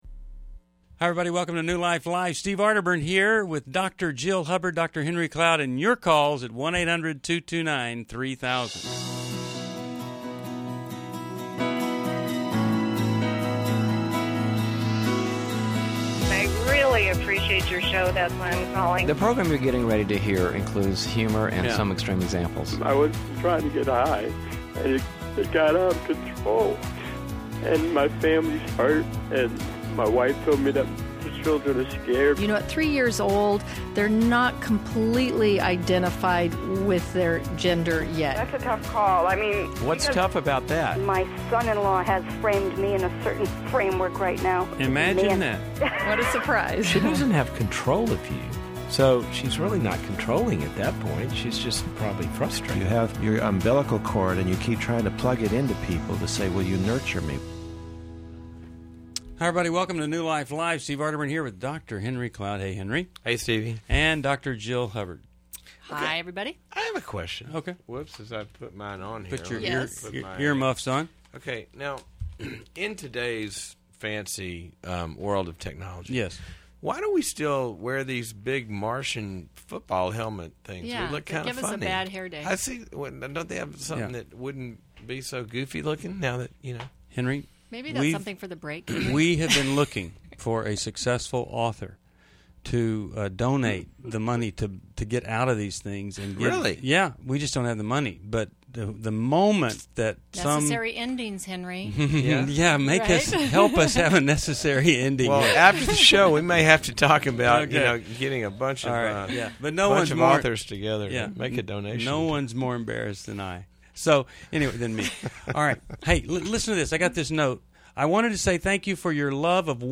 Explore dating, grief, and marriage insights on New Life Live: March 21, 2011. Hosts offer guidance for navigating life's challenges with faith.